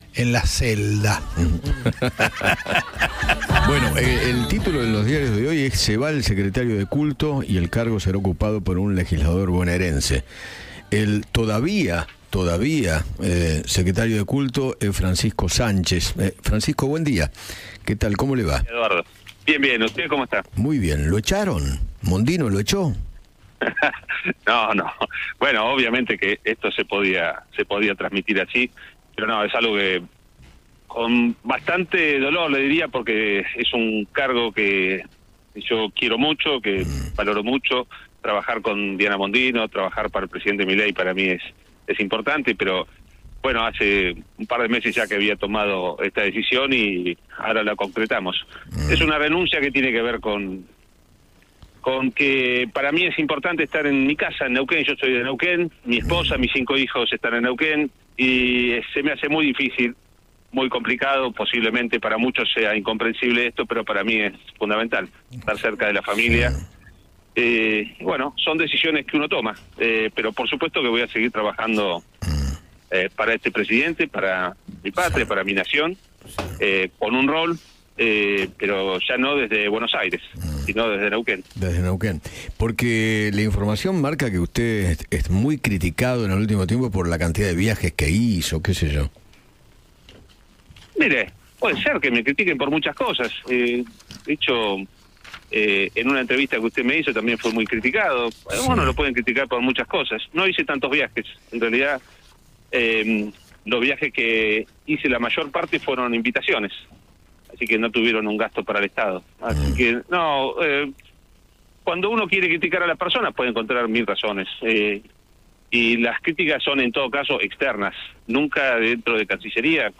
Francisco Sánchez conversó con Eduardo Feinmann sobre los motivos que lo llevaron a renunciar como secretario de Culto.